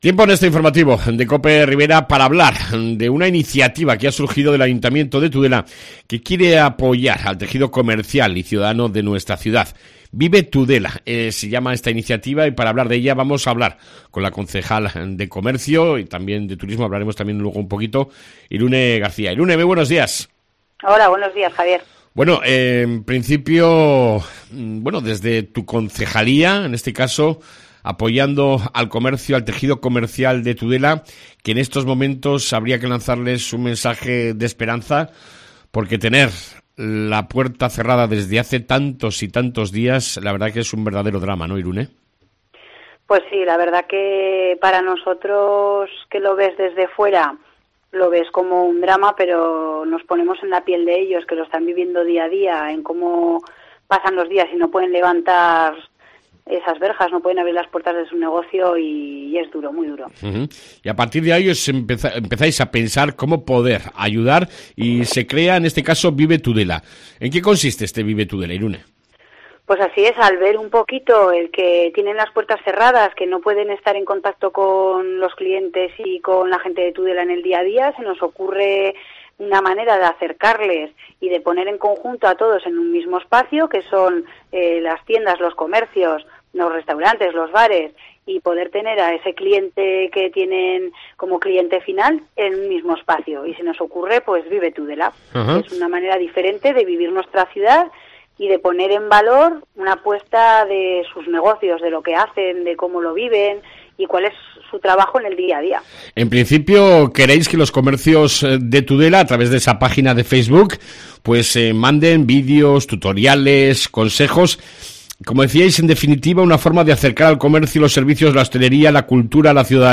AUDIO: Hablamos con la Concejal de Comercio y Turismo Irune Garcia Garcia para que nos cuente algo más de la iniciativa Vive Tudela